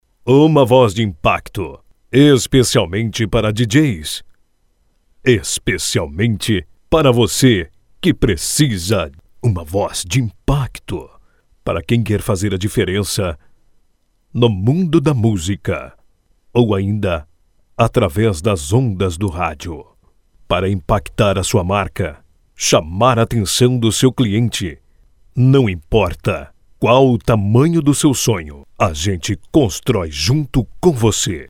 IMPACTO: